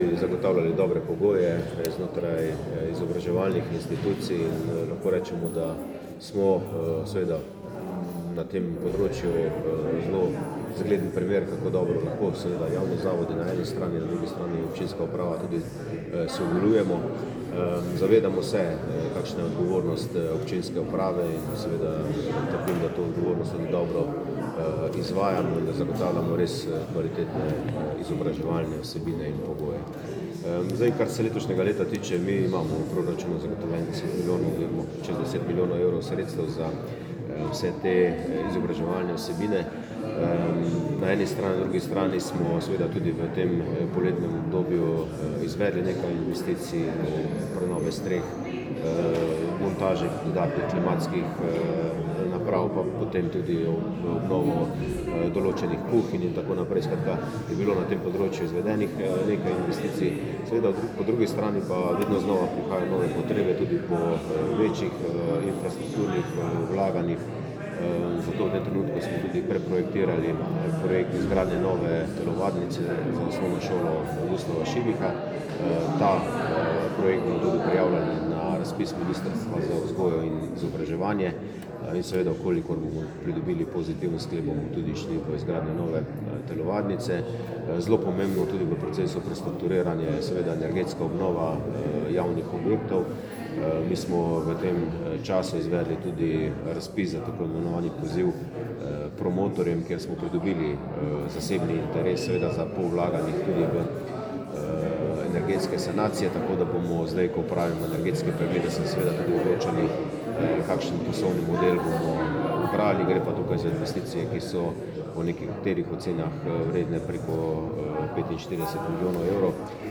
Izjava župana Mestne občine Velenje Petra Dermola